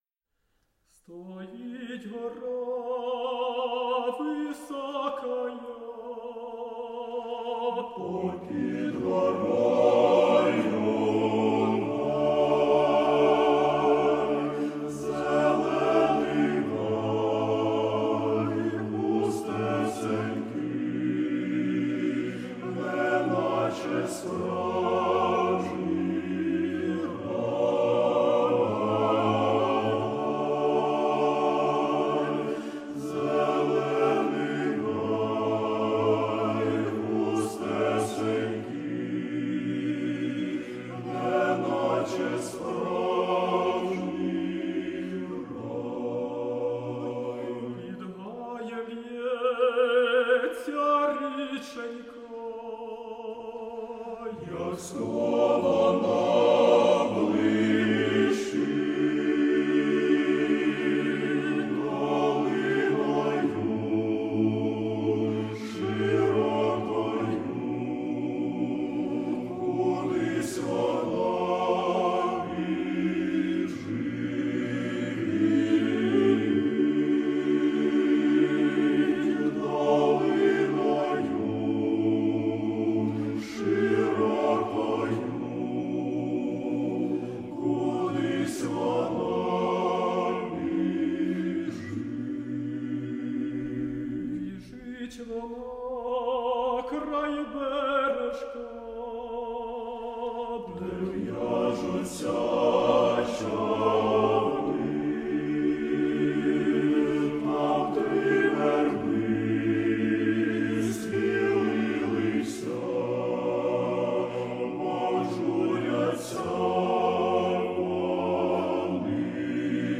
Хор Свято-Ильинского Храма г. Киева - Стоит гора высокая